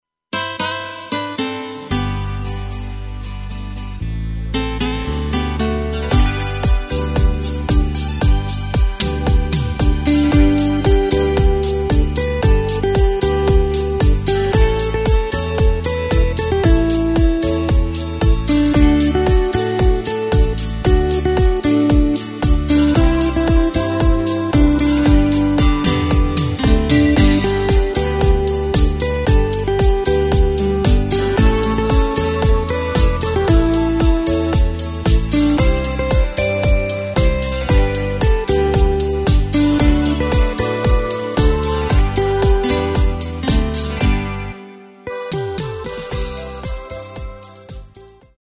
Takt:          4/4
Tempo:         114.00
Tonart:            G
Schlager aus dem Jahr 2024!
Playback mp3 Demo